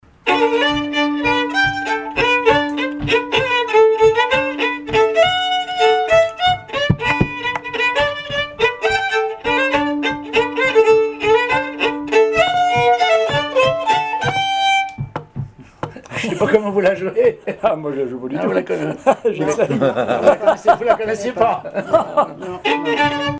danse : valse
Pièces instrumentales à plusieurs violons
Pièce musicale inédite